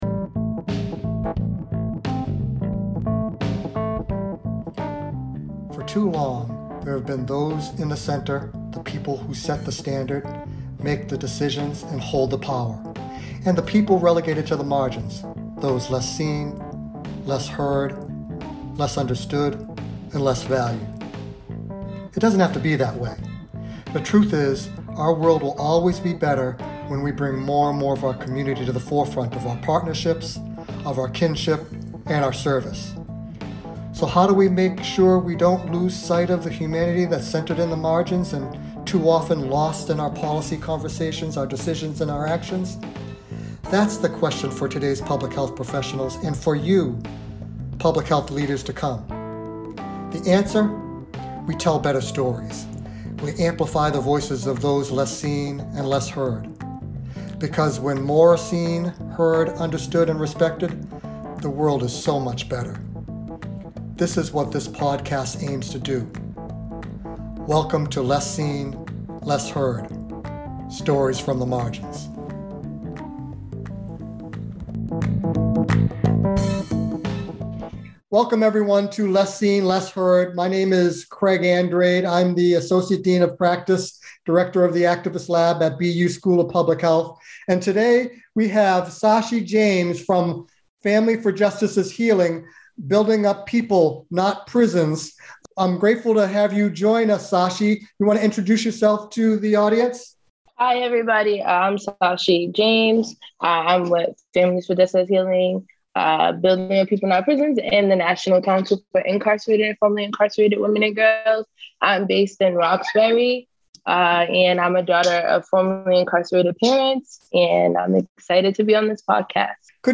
Listen to the full conversation here, or read the unedited transcript.